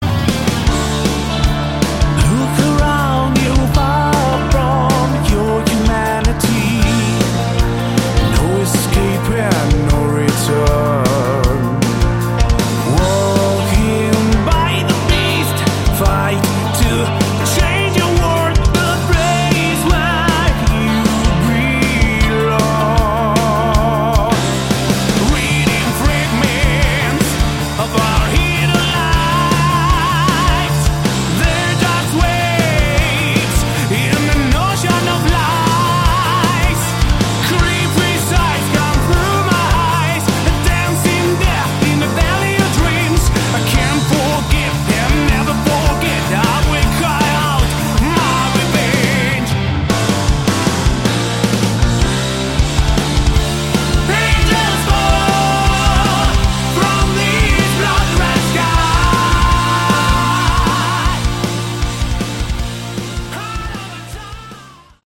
Category: Melodic Prog Rock
vocals
guitars
keyboards
bass
drums